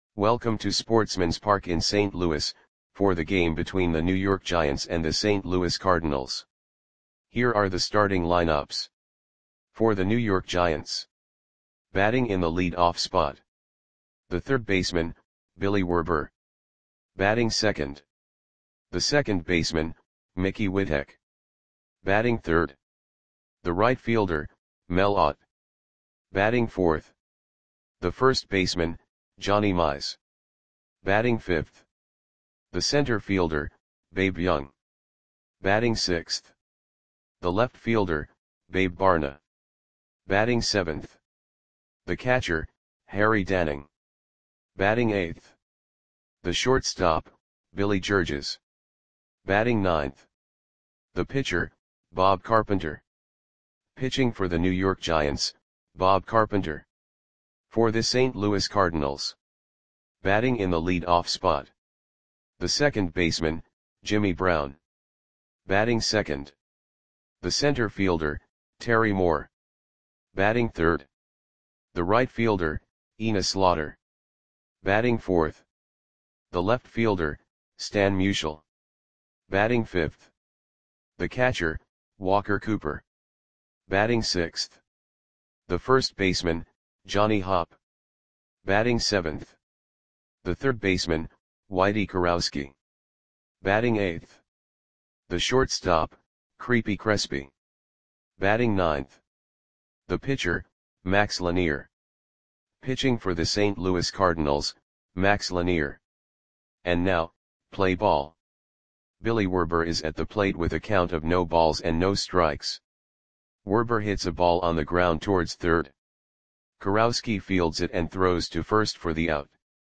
Audio Play-by-Play for St. Louis Cardinals on September 2, 1942
Click the button below to listen to the audio play-by-play.